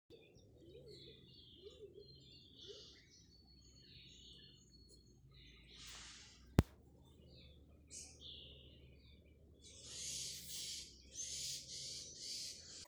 клинтух, Columba oenas
Ziņotāja saglabāts vietas nosaukumsValle
СтатусПоёт